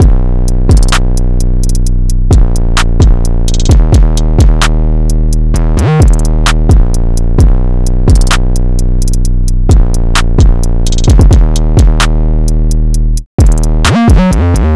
drumloop 2 (130 bpm).wav